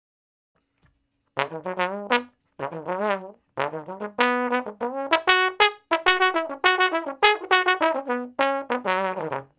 099 Bone Straight (Db) 08.wav